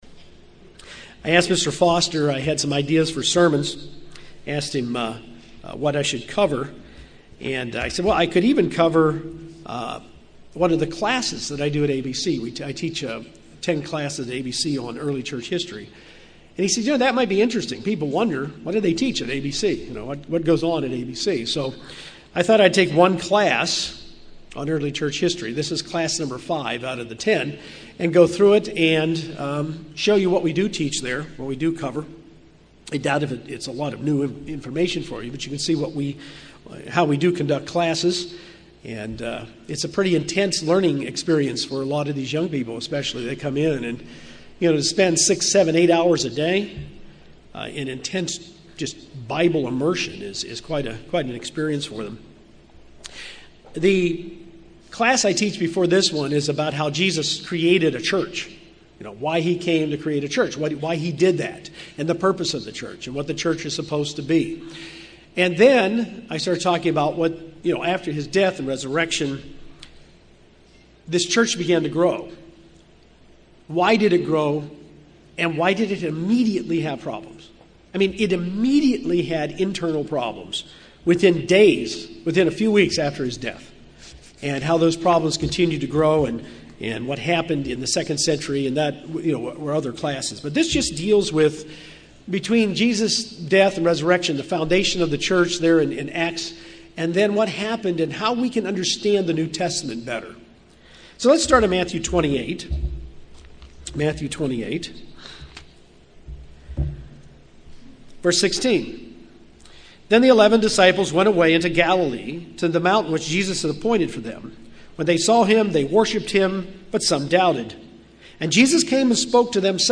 This sermon covers the 5th class on Church History covered at the ABC Class. What was the church like in the first century after the death of Jesus Christ?